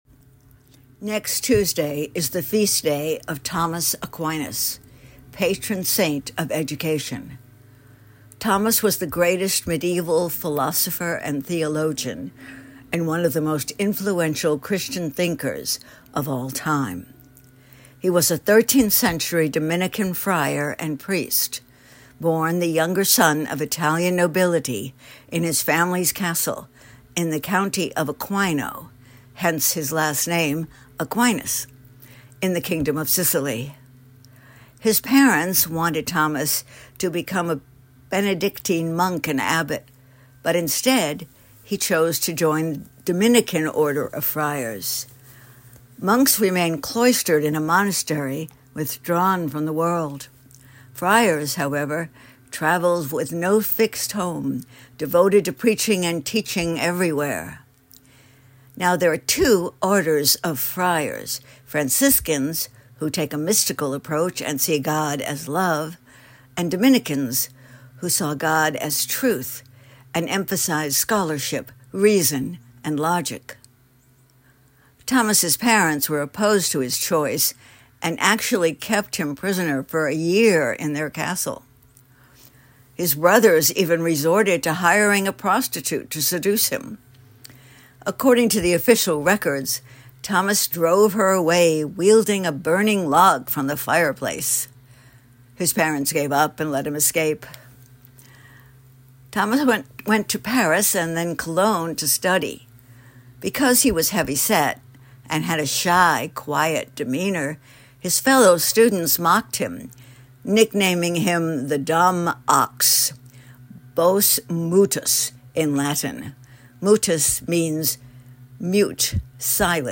talk on Aquinas.